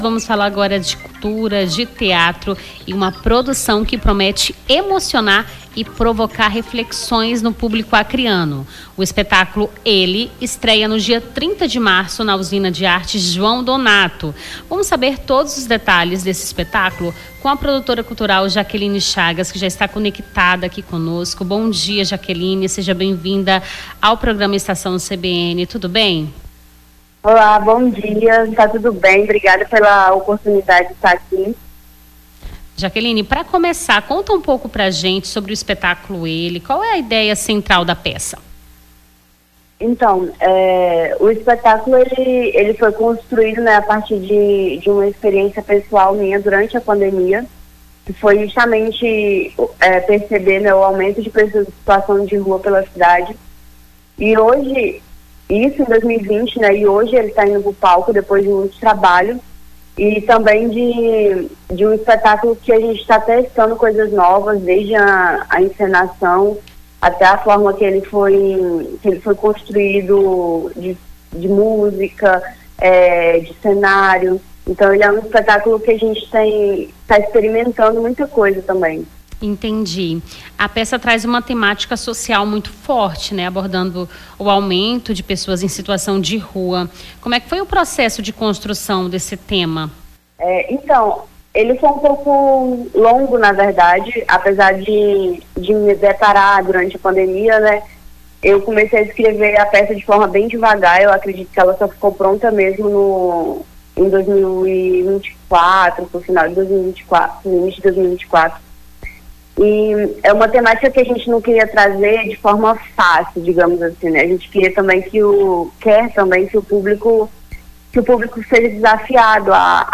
Nome do Artista - CENSURA - ENTREVISTA ESPETÁCULO TEATRAL CANDEEIRO (22-03-25).mp3